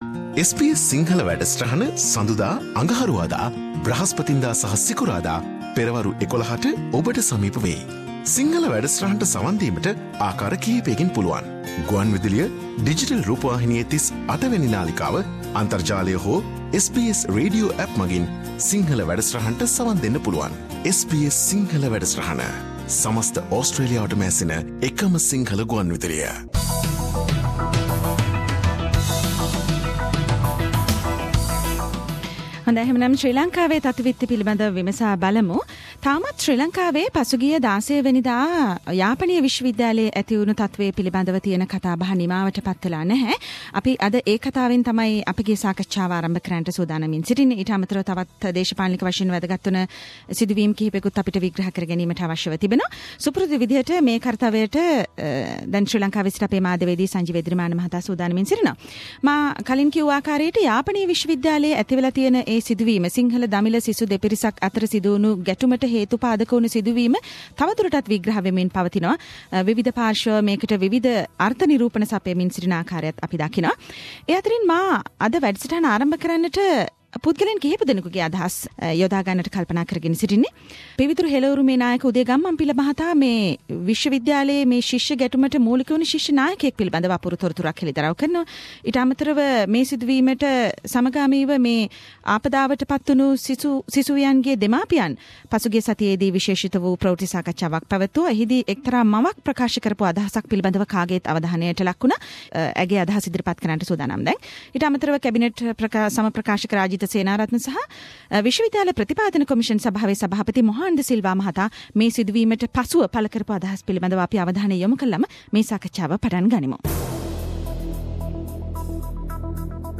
SBS Sinhalese weekly Sri Lankan news wrap - aftermath of the Jaffna Uni clash